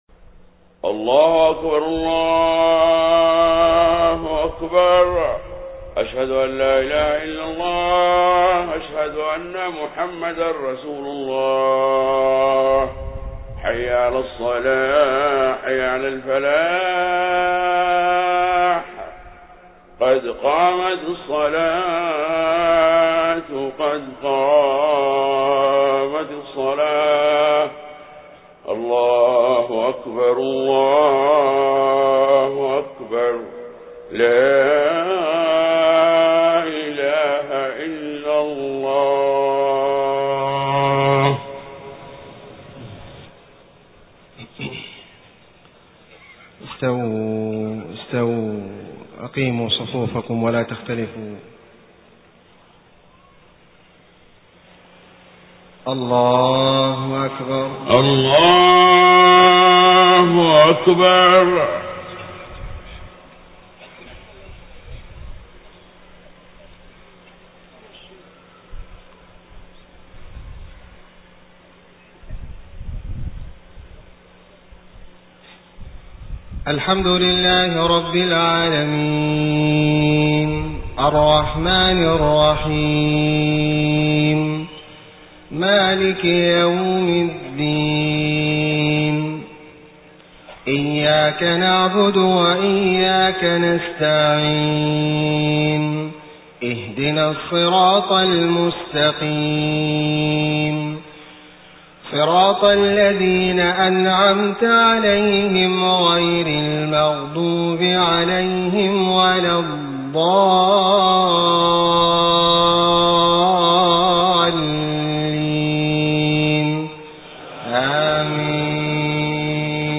صلاة العشاء 25 صفر 1431هـ من سورة النحل 93-103 > 1431 🕋 > الفروض - تلاوات الحرمين